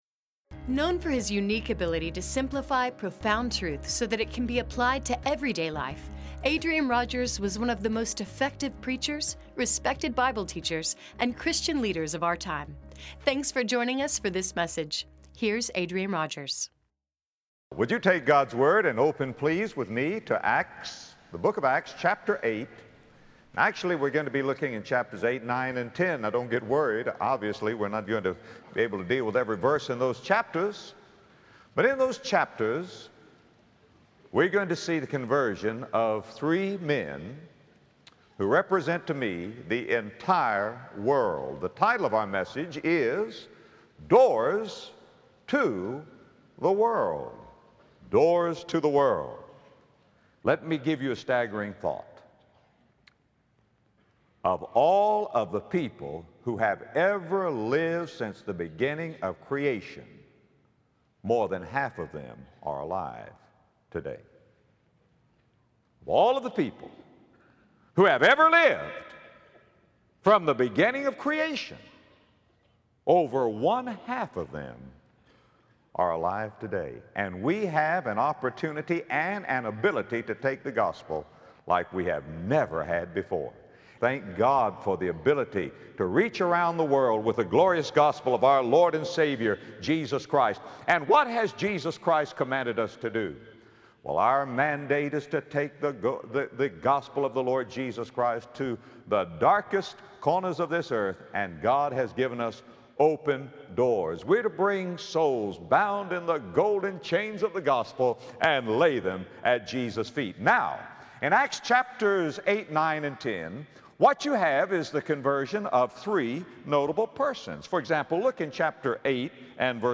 Each of us is called, ordained, and equipped to share the Gospel of Jesus Christ. In this message from the Book of Acts, Adrian Rogers shares the conversion stories of three men to reveal why it is so important to share the good news.